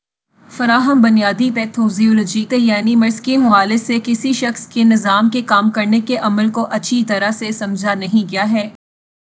Spoofed_TTS/Speaker_04/113.wav · CSALT/deepfake_detection_dataset_urdu at main